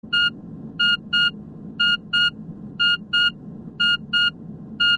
• BEEPING NOISE ALARM CLOCK.wav
BEEPING_NOISE_ALARM_CLOCK_Iw5.wav